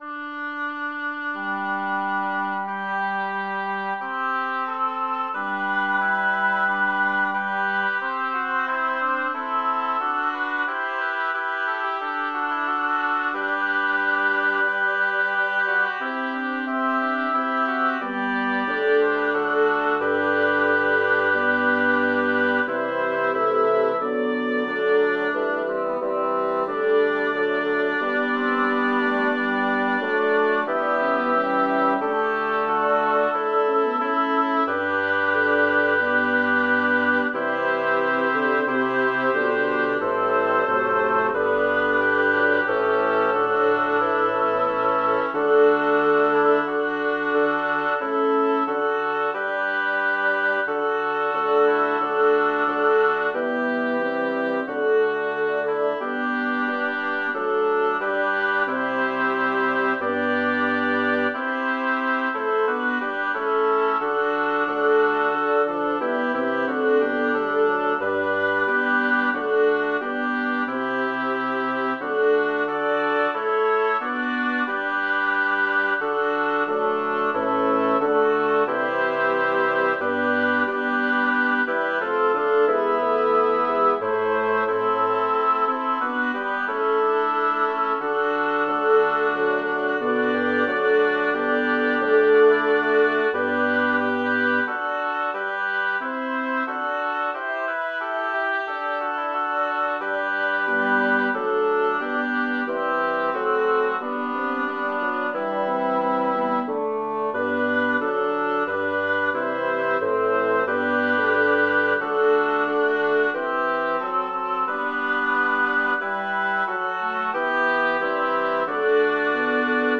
Title: O quam gloriosum est regnum Composer: Curtio Valcampi Lyricist: Number of voices: 6vv Voicing: SAATTB Genre: Sacred, Motet
Language: Latin Instruments: A cappella
Score information: A4, 5 pages, 150 kB Copyright: CPDL Edition notes: Transposed up a major second.